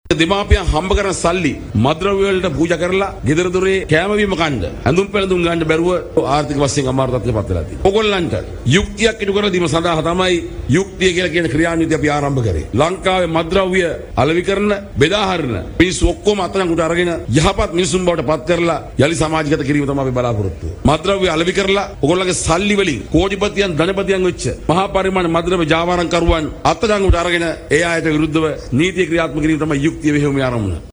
යුක්තියේ යුතුකම ගැන වැඩබලන පොලිස්පතිගෙන් ප්‍රකාශයක්
මේ අතර කොළඹදී මාධ්‍ය වෙත අදහස් දක්වමින් වැඩබලන පොලිස්පති දේශබන්දු තෙන්නකෝන් මහතා සඳහන් කලේ ජනතාවට යුක්තිය ඉටු කිරීමේ අරමුණින් මෙම යුක්තිය මෙහෙයුම ආරම්භ කල බවයි.